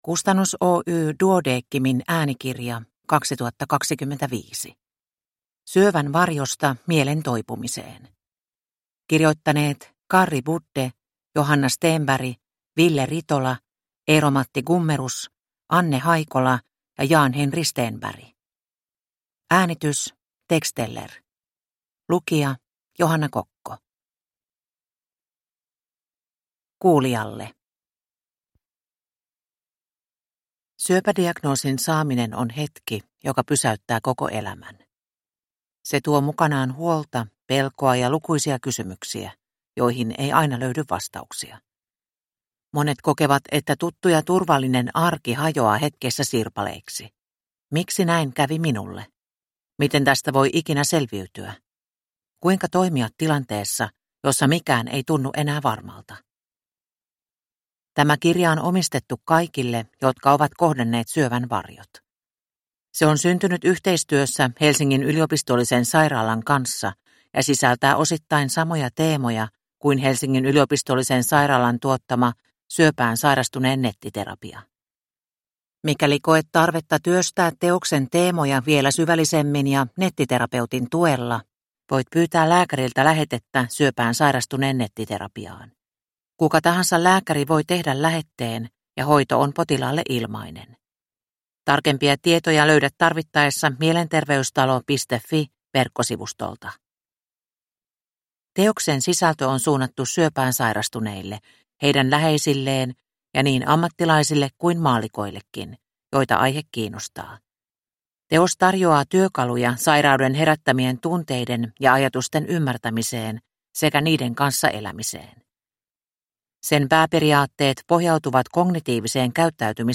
Syövän varjosta mielen toipumiseen – Ljudbok